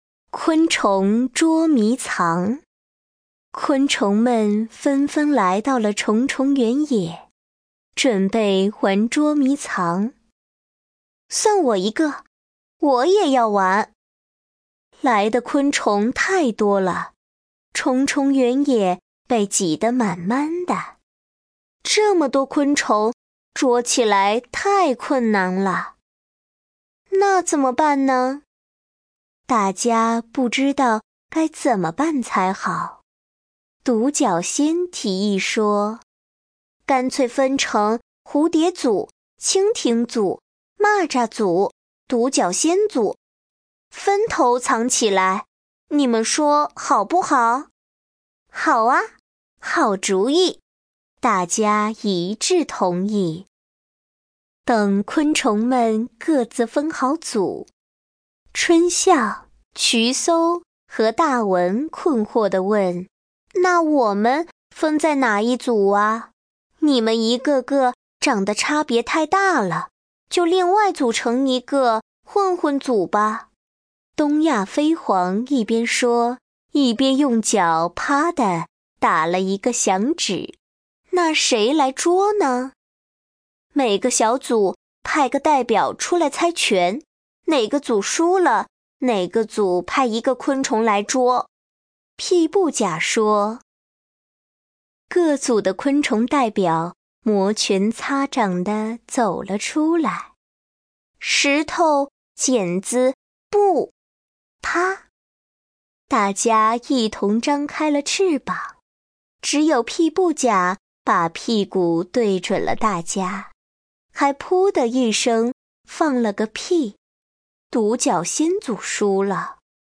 点击播放昆虫智趣园5-昆虫捉迷藏绘本故事音频